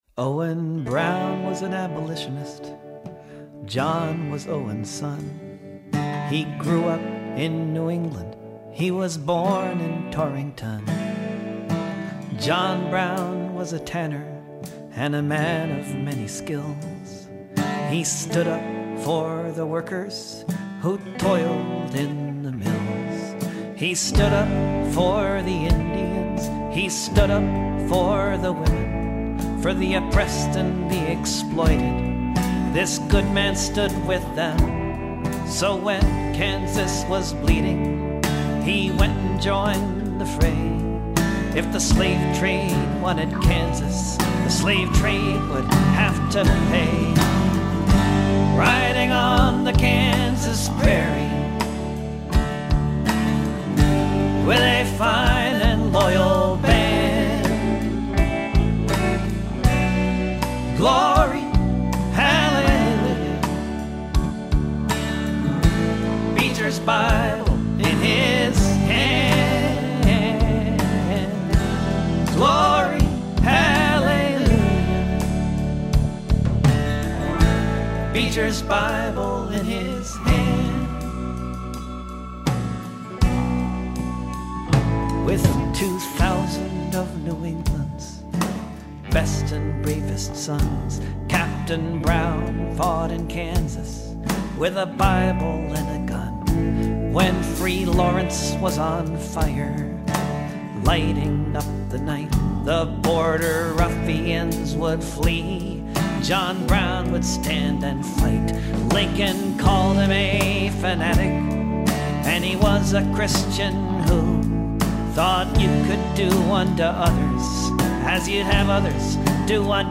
solo acoustic CD